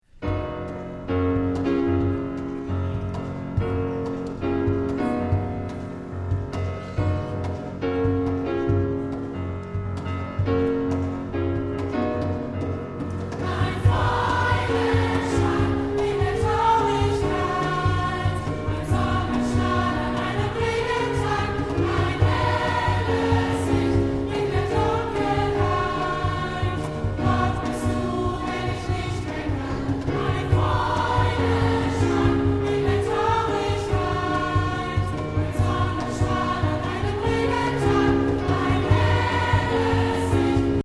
Chor, Cajon